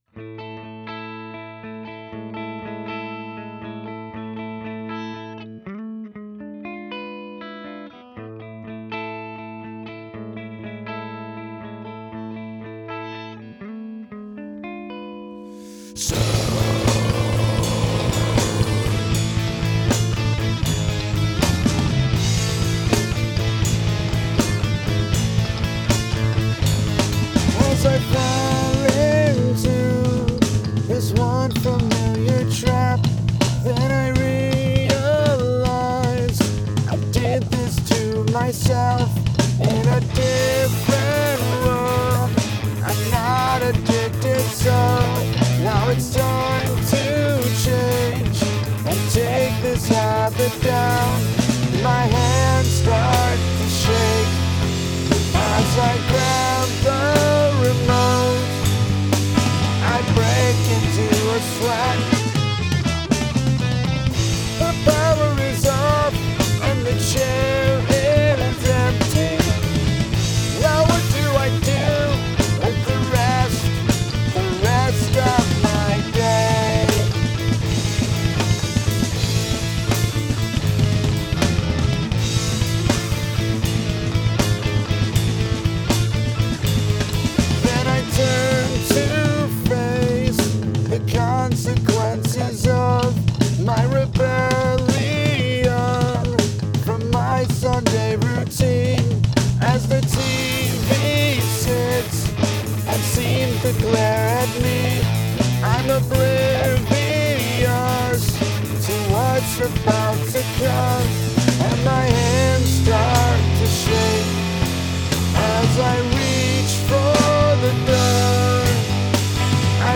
Well, after a fun little diversion, it’s back to the heavy stuff this week.
In order to get a more accurate depiction, I did take a copy of the main vocal track and shifted the pitch up an octave (but I buried it in the mix more than Cynic does, so there’s that). Also, it turns out I can, in fact, still play some haulin’ double kick drum parts!
And, as a final note, I think this guitar solo is a personal best for me.